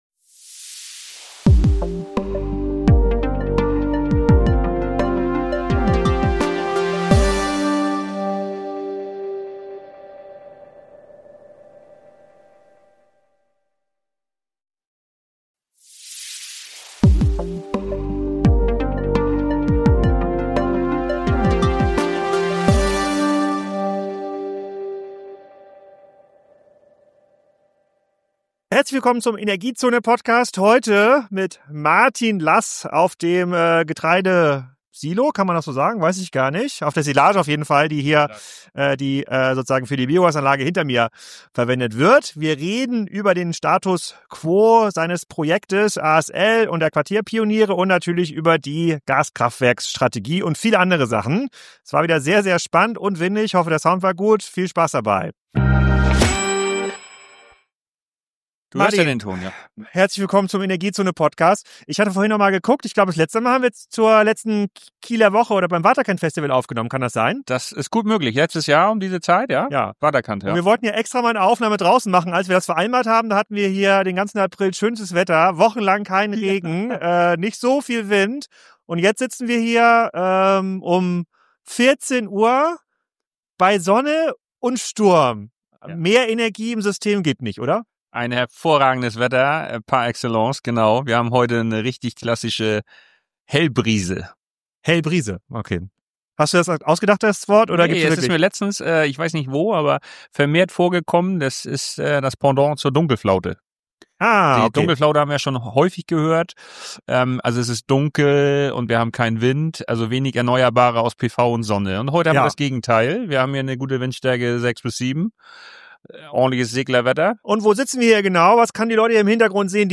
Wir befinden uns auf dem Silagehaufen einer Biogasanlage in Tüttendorf, umringt von der Biomasse, die als Energiespeicher dient.